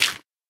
sounds / dig / gravel3.ogg
gravel3.ogg